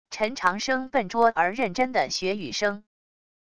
陈长生笨拙而认真的学语声wav音频